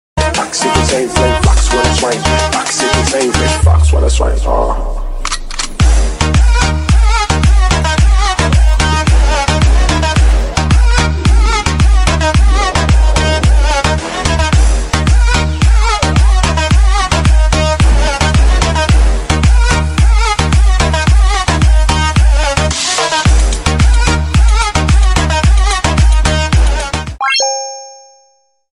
Merry Audio Neo tweeter speaker